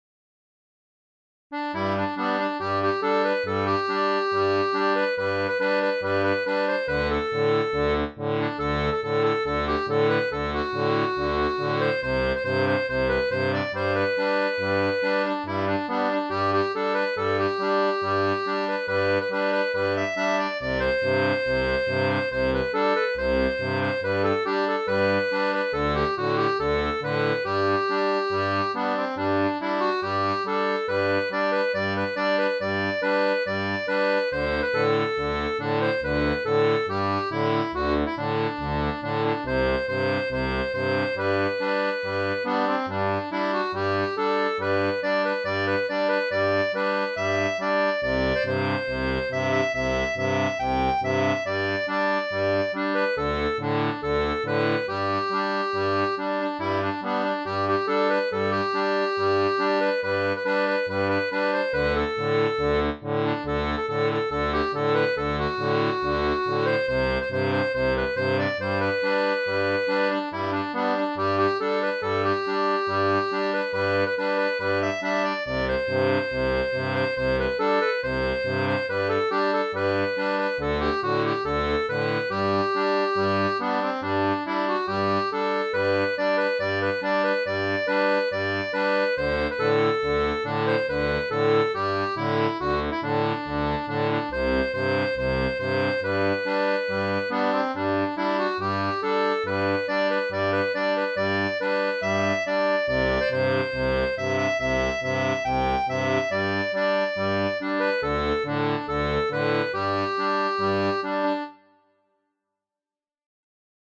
Chanson populaire de Monte Sant'Angelo (Italie)
Musique traditionnelle